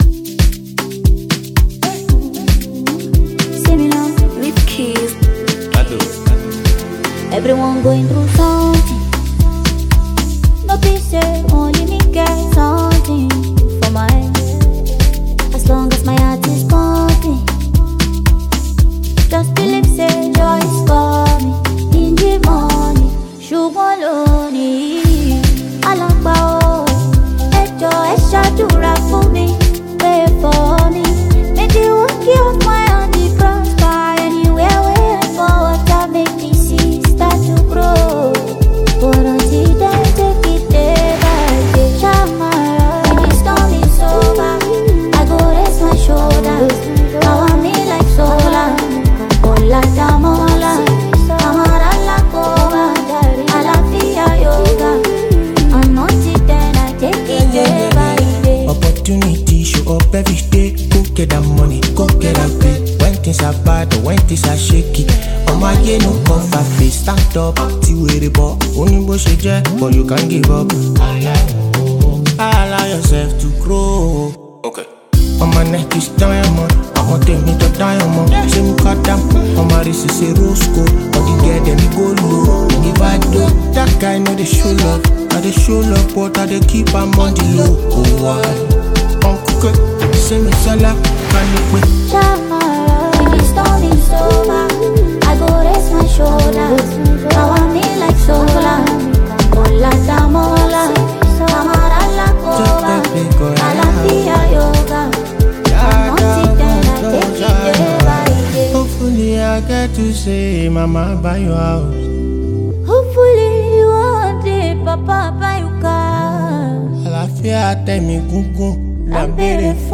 Afro-pop
smooth Afrobeat rhythms